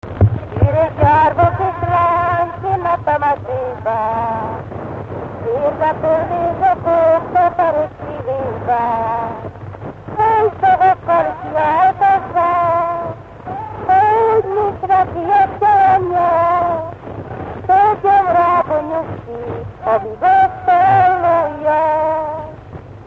Dunántúl - Somogy vm. - Balatoncsehi
Stílus: 8. Újszerű kisambitusú dallamok
Kadencia: V (V) 2 1